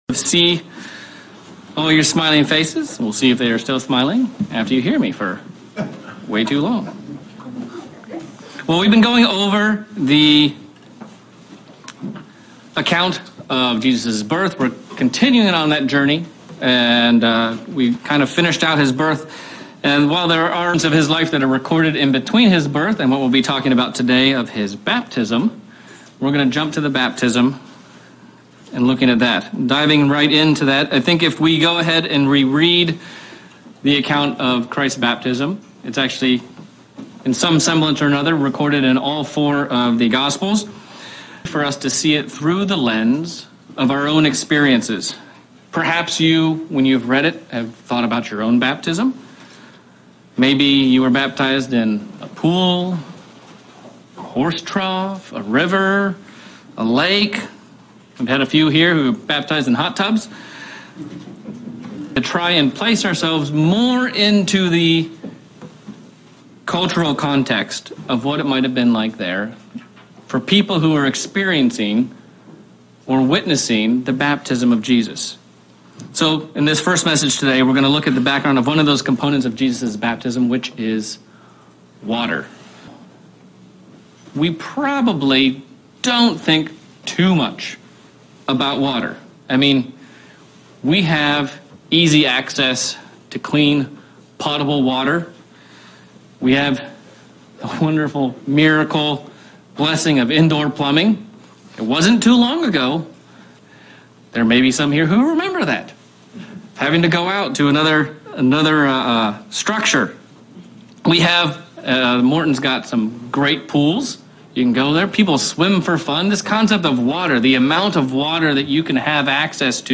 Sermon looking at the baptism of Christ and the significance of washing of water and its significance to Jesus Christ.